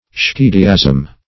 Search Result for " schediasm" : The Collaborative International Dictionary of English v.0.48: Schediasm \Sche"di*asm\, n. [Gr.